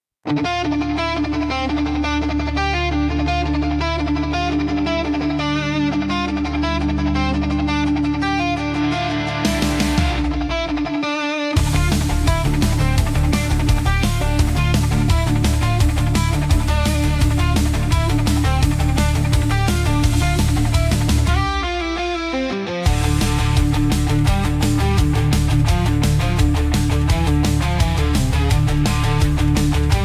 20 pistes uniques mêlant rétro et modernité